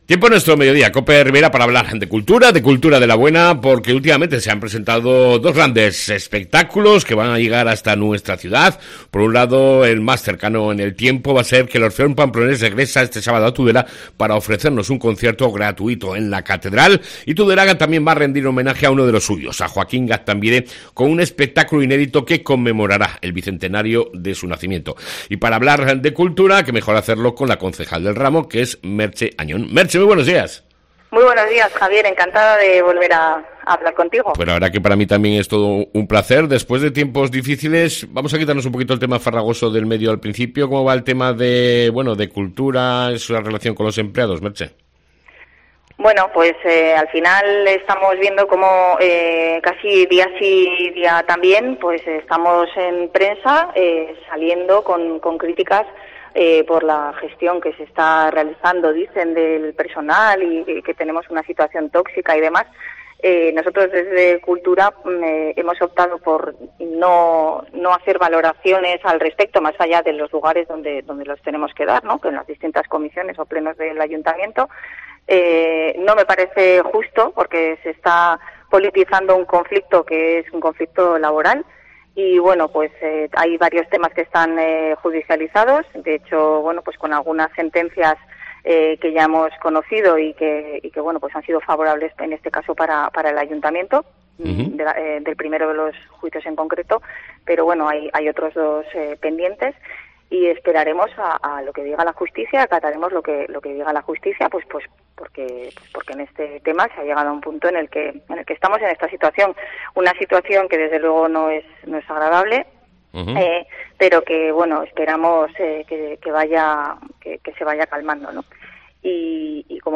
ENTREVISTA CON LA CONCEJAL DE CULTURA MERCHE AÑON